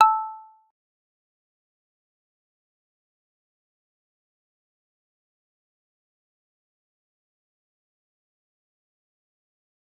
G_Kalimba-A6-mf.wav